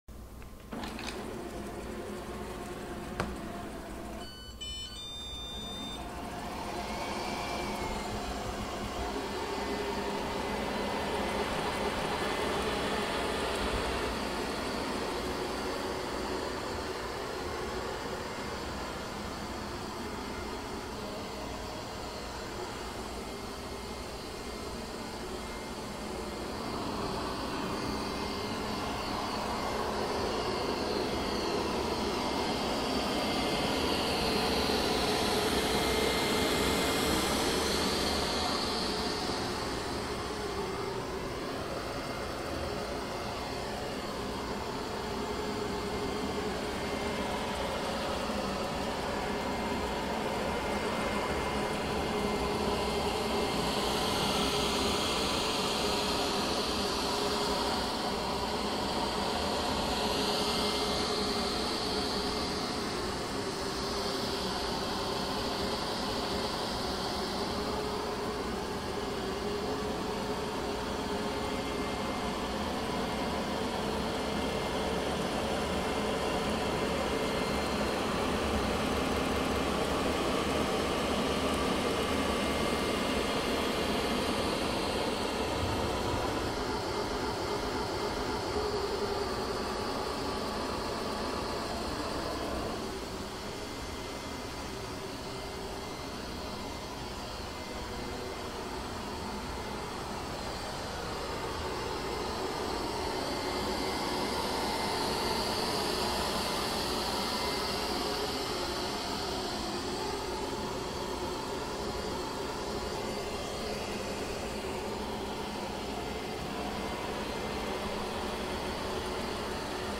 Звуки робота пылесоса
Этот монотонный гул идеально подходит для использования в качестве фонового белого шума, который помогает сконцентрироваться, расслабиться или замаскировать посторонние шумы.